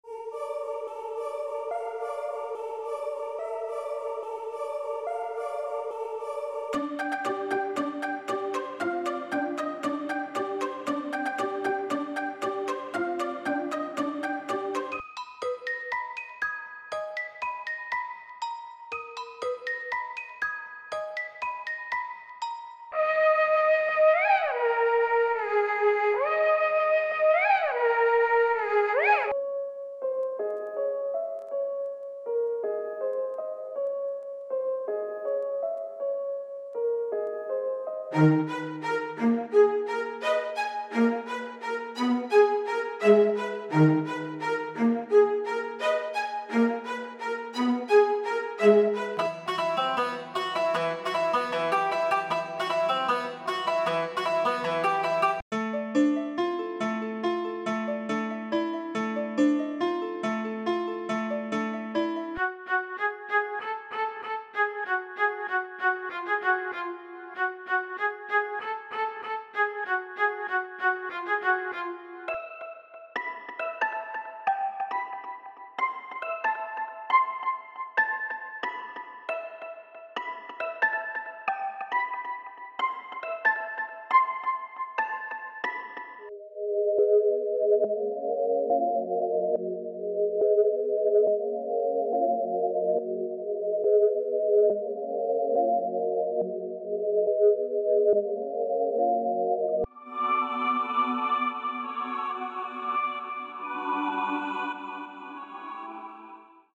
• 16 Melody Loops